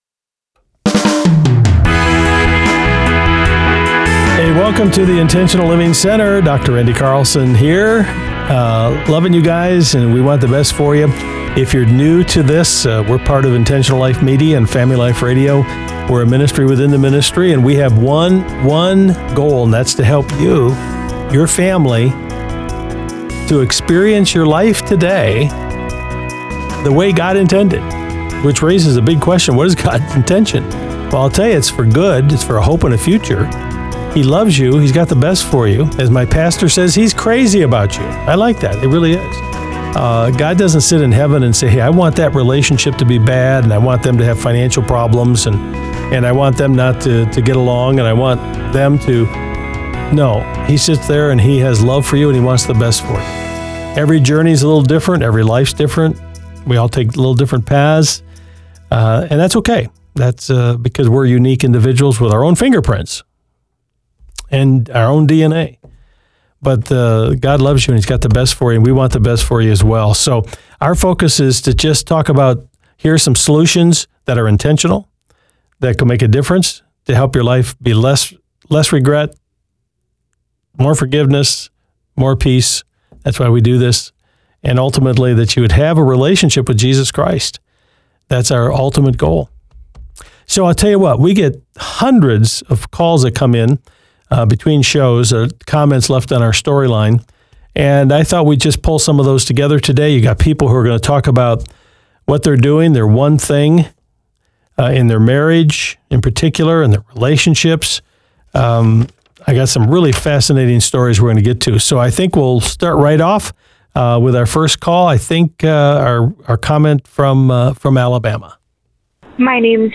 1 Jesus Christ is Lord / / Live Bible Study Series / / Pt.3 33:50